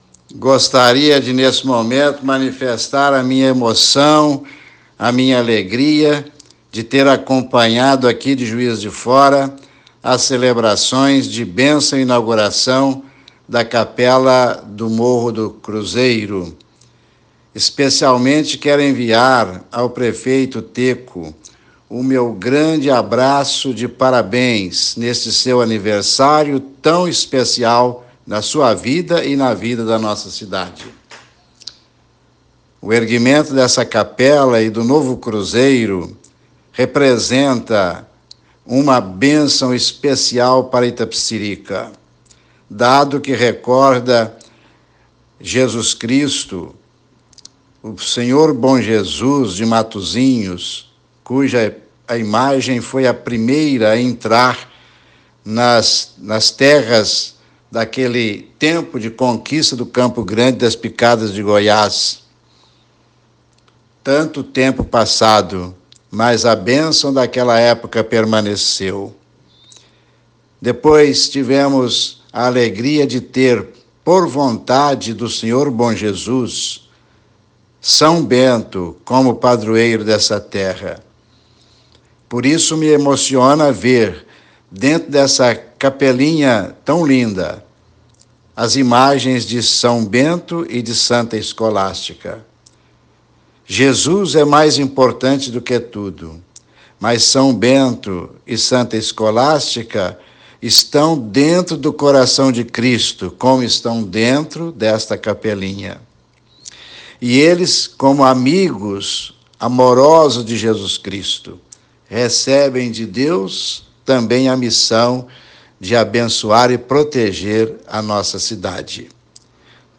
Confira no vídeo abaixo Ouça a mensagem de Dom Gil Antônio Moreira, arcebispo de Juiz de Fora.
Mensagem-de-Dom-Gil-Antonio-Moreira-ao-prefeito-Teko.mp3